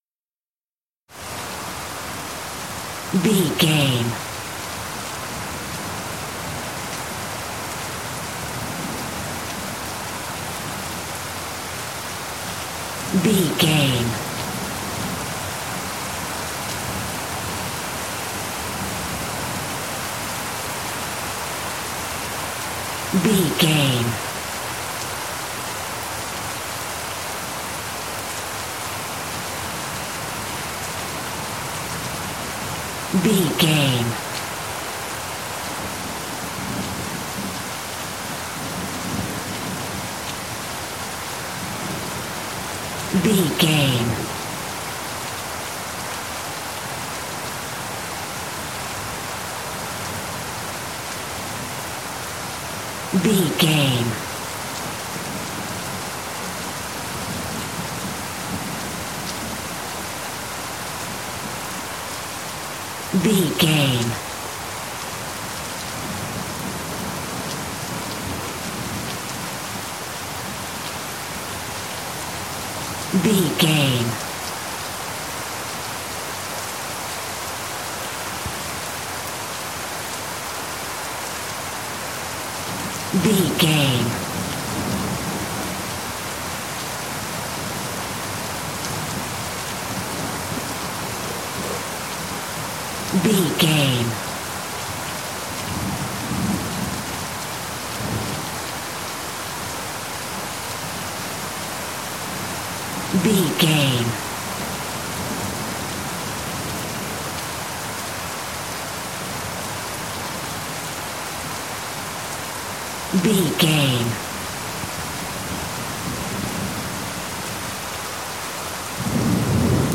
City rain heavy thunder
Sound Effects
No
chaotic
urban
ambience